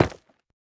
Minecraft Version Minecraft Version latest Latest Release | Latest Snapshot latest / assets / minecraft / sounds / block / nether_ore / break1.ogg Compare With Compare With Latest Release | Latest Snapshot